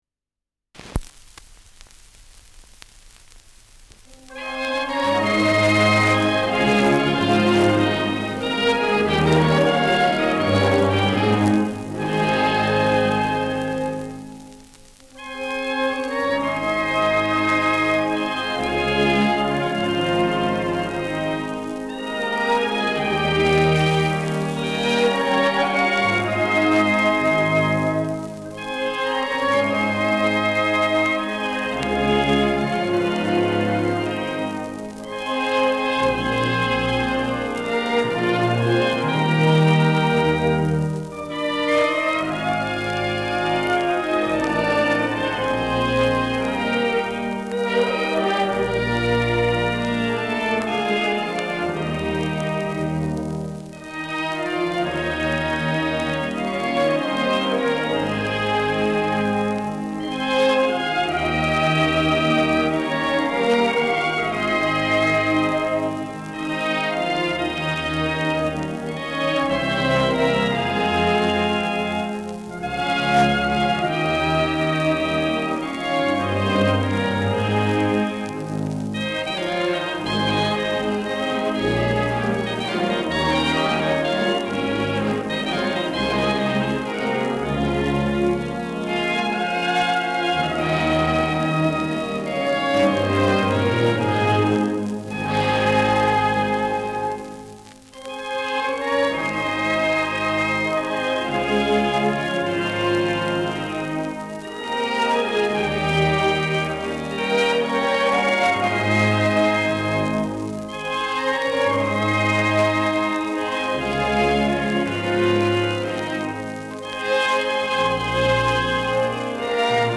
Schellackplatte
Abgespielt : Erhöhtes Grundrauschen : Erhöhter Klirrfaktor : Leiern : Teils stärkeres Rauschen : Gelegentlich leichtes Knacken
Große Besetzung mit viel Hall, die einen „symphonischen Klang“ erzeugt.
[Berlin] (Aufnahmeort)